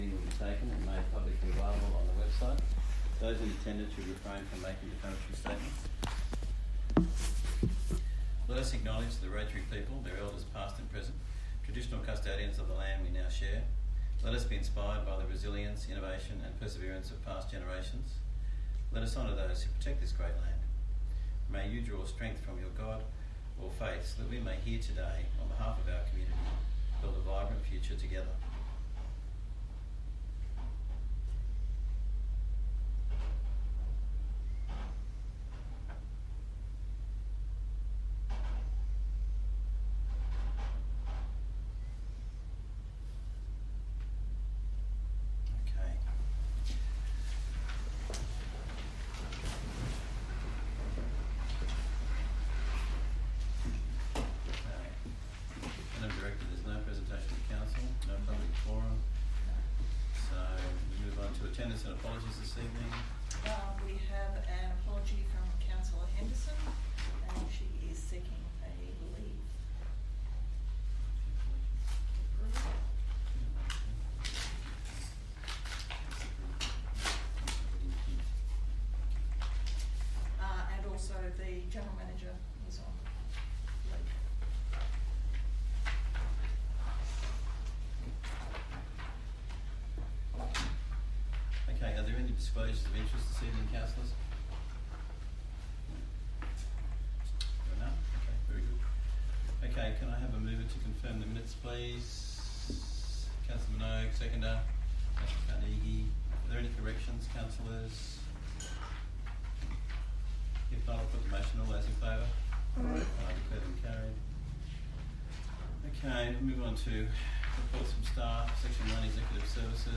17 December 2024 Ordinary Council Meeting
Bland Shire Council Chambers, 6 Shire Street, West Wyalong, 2671 View Map